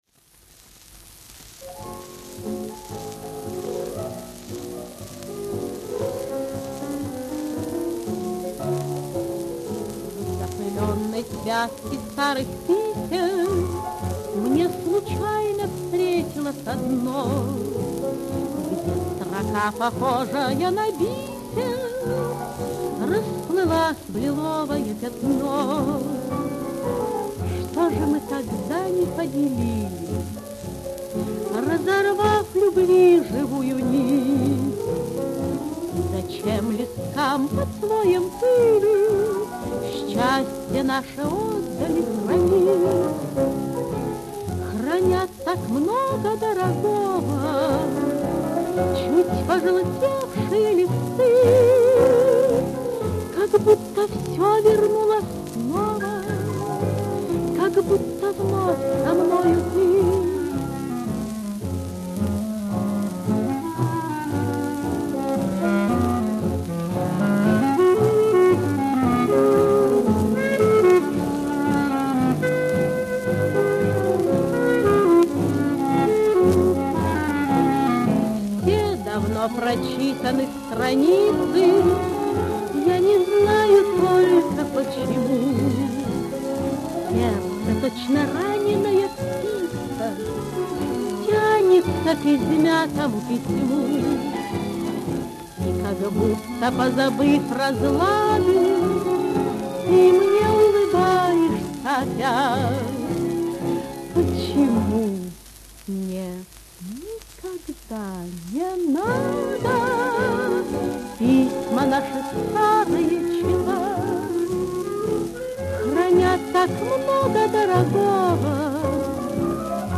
Оркестр